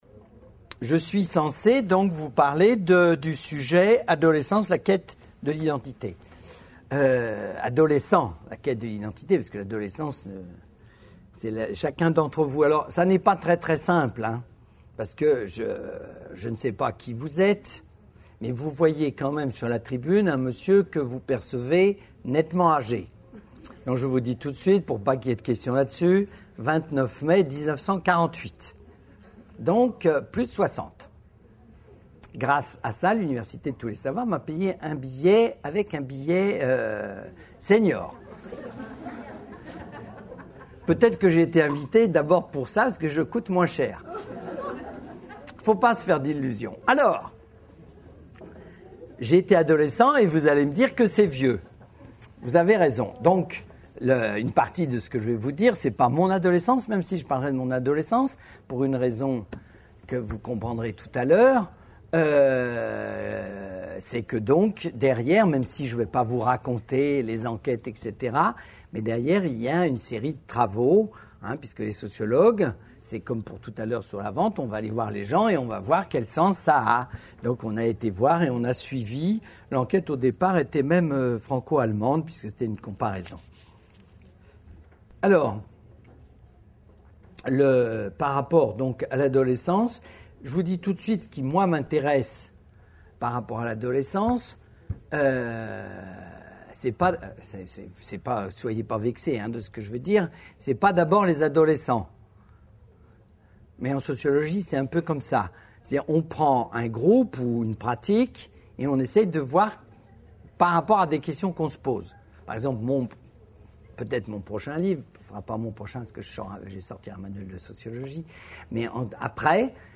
Une conférence de l'UTLS au Lycée Adolescence, la quête de l'identité par François de Singly Lycée Tregey (33 Bordeaux)